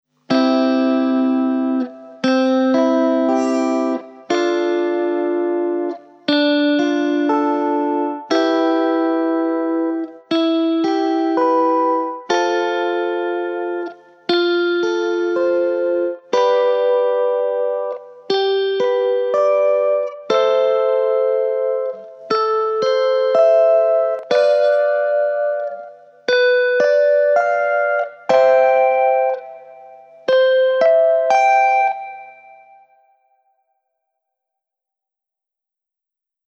♫ 7 Stufen der Stufentheorie mit C-Dur-Tonika
quintenzirkel_gitarre__stufentheorie.mp3